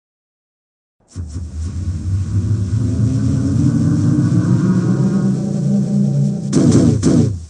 科幻小说 " 轨道枪3发
描述：铁枪射击的三枪
Tag: 未来派 外来声音效果 未来 外星人 武器 空间 科幻